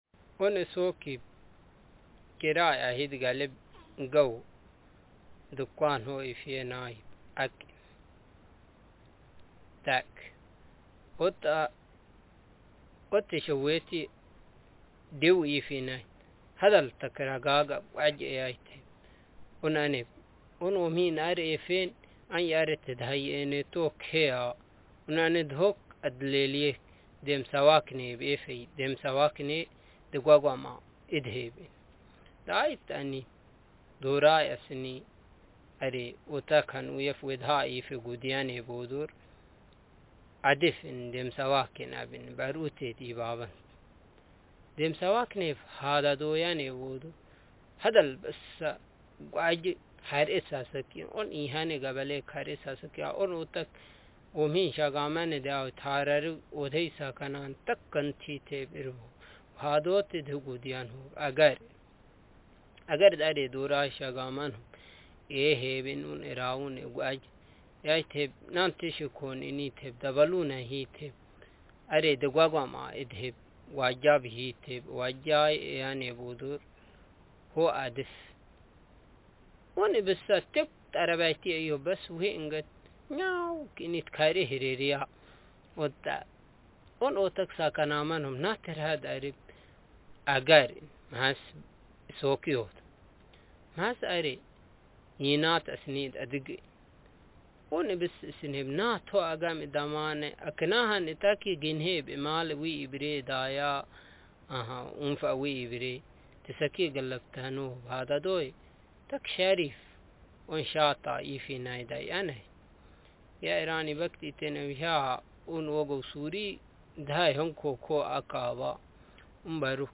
Speaker age40
Speaker sexm
Text genrepersonal narrative